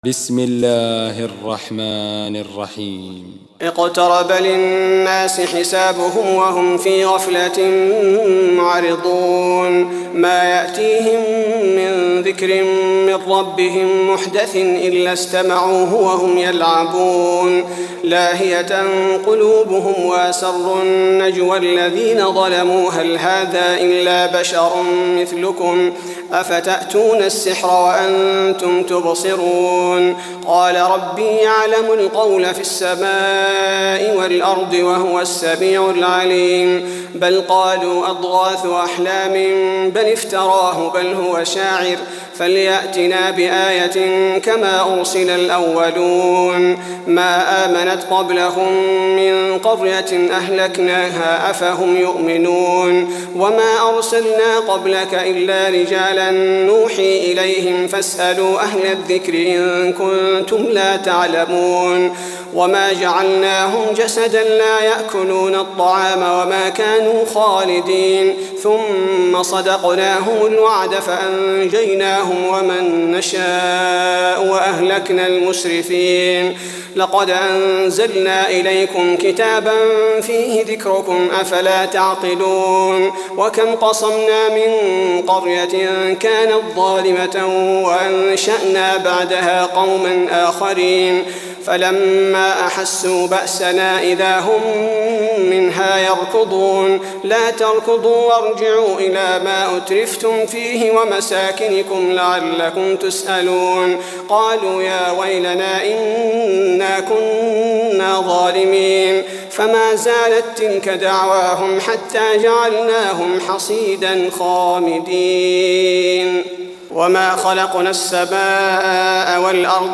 تراويح الليلة السادسة عشر رمضان 1423هـ من سورة الأنبياء (1-82) Taraweeh 16 st night Ramadan 1423H from Surah Al-Anbiyaa > تراويح الحرم النبوي عام 1423 🕌 > التراويح - تلاوات الحرمين